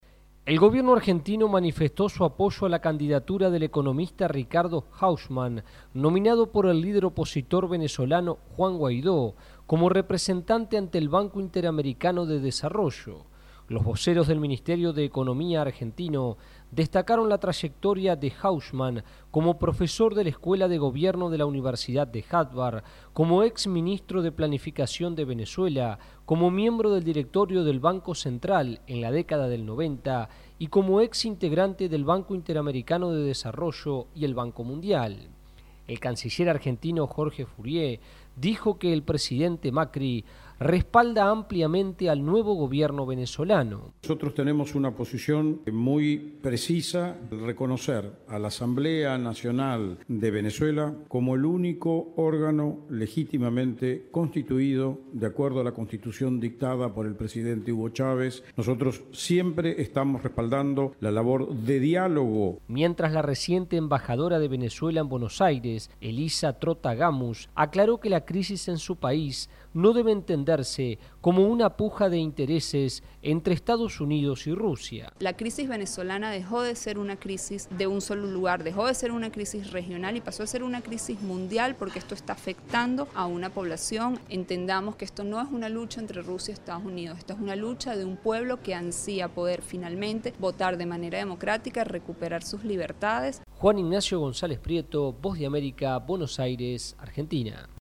VOA: Informe desde Argentina